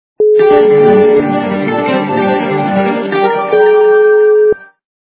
Звук для СМС - Гитара Звук Звуки Звук для СМС - Гитара
При прослушивании Звук для СМС - Гитара качество понижено и присутствуют гудки.